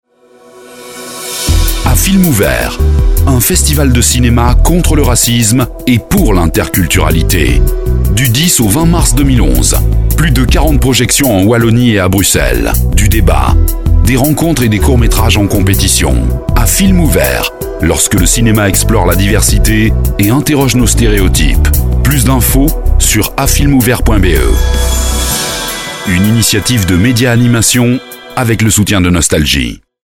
Spot radio - A Films Ouverts